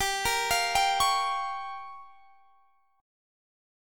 Gm7b5 Chord
Listen to Gm7b5 strummed